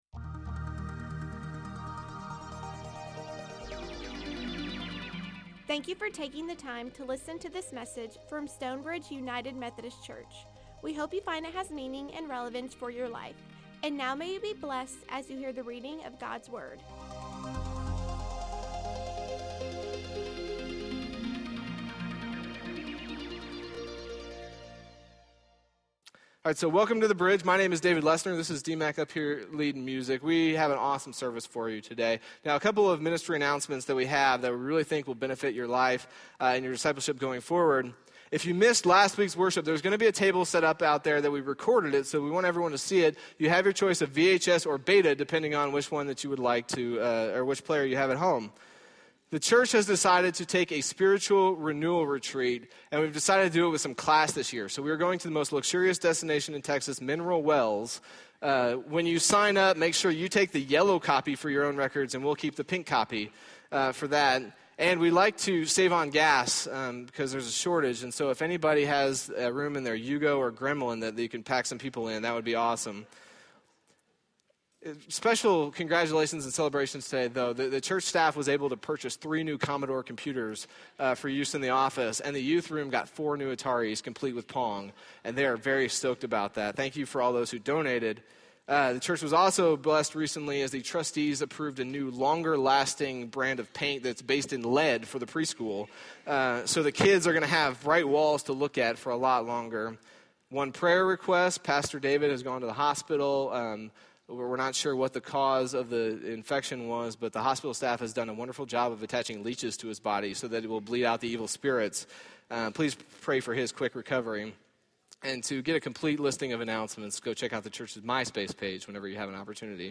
Recorded live at Stonebridge United Methodist Church in McKinney, TX.